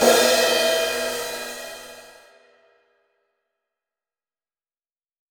Cymbal OS 03.wav